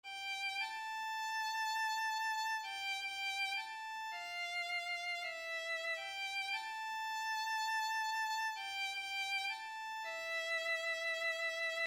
大提琴的力量和作用大提琴
描述：大提琴循环；你可以在我的循环人资料页面上找到这个节拍的更多部分和其他很酷的东西希望你能找到有用的东西。
Tag: 90 bpm Hip Hop Loops Strings Loops 1.79 MB wav Key : Unknown